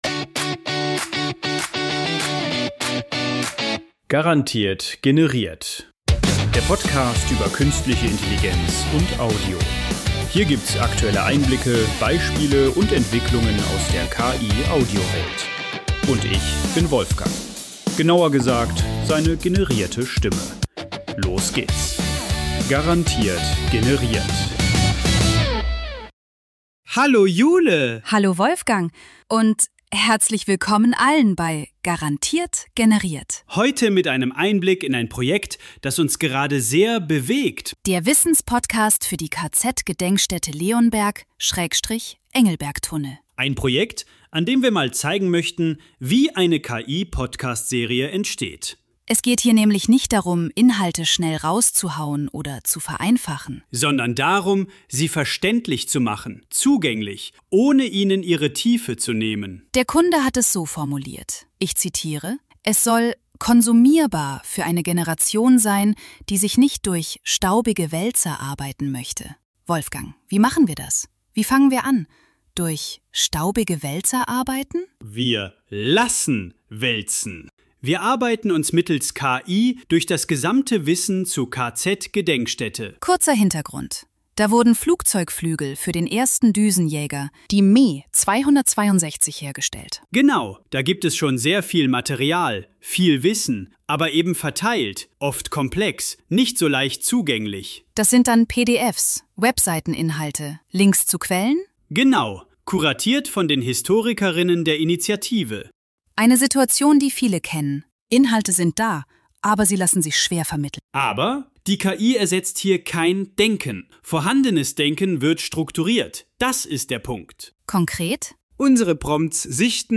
Inhalte, Töne, Dialoge, Sounds künstlich generiert.